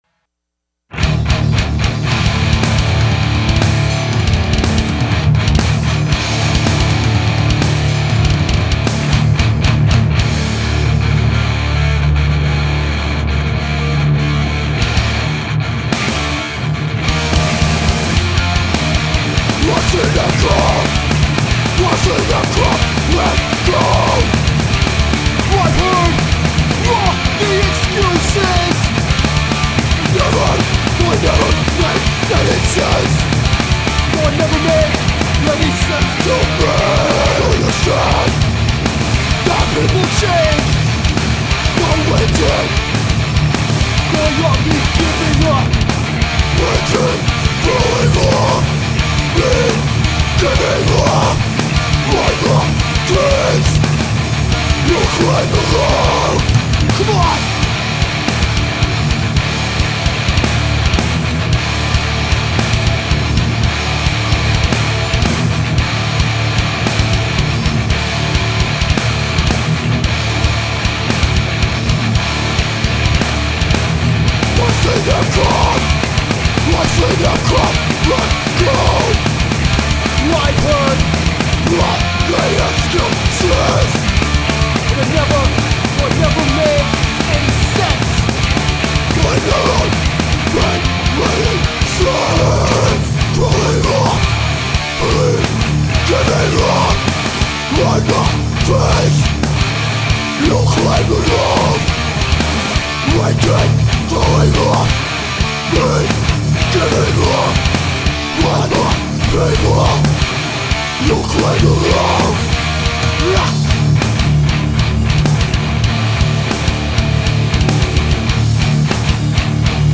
Vocals
Guitar
Bass
Drums
Bobby Peru Recording Studios, Milwaukee, WI.
Hardcore , Straight Edge